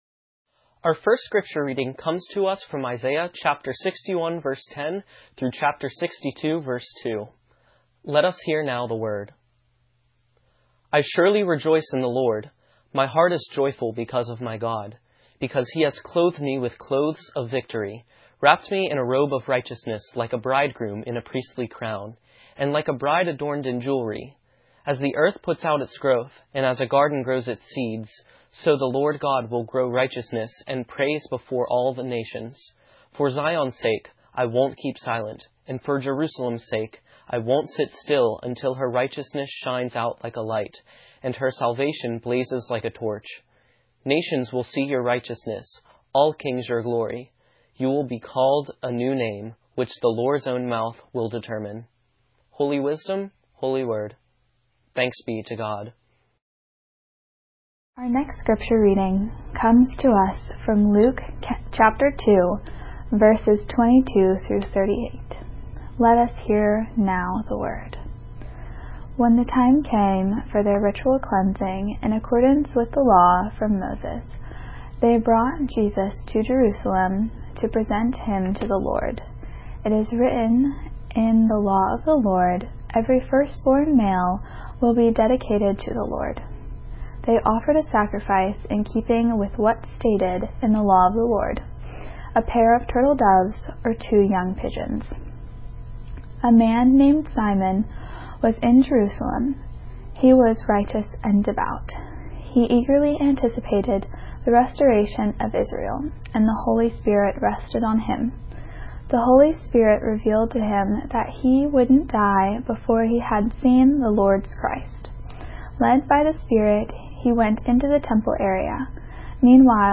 with readings by UKIRK students
(streamed via Facebook)